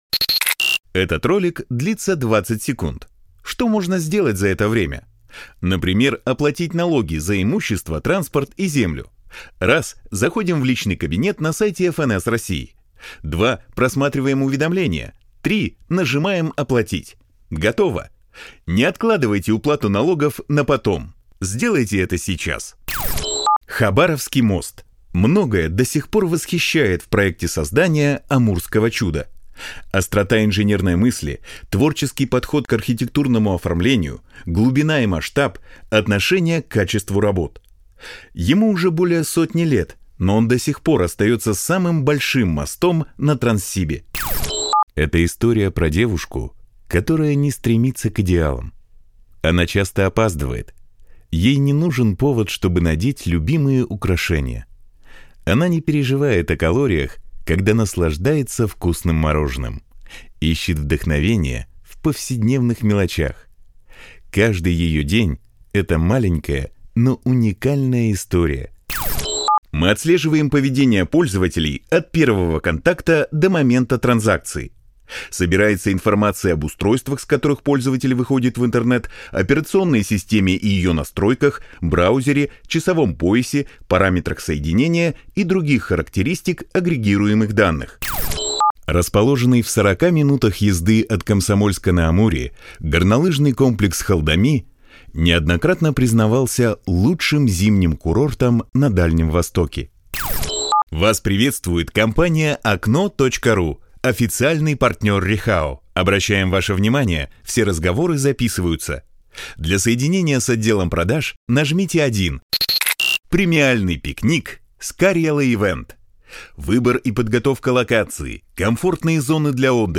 Баритон.
Информационная; нейтральная; игровая; экспрессивная подача.
Тракт: Микрофон: Neumann TLM 103 Преамп: DBX 376 tube Карта: ESI MAYA44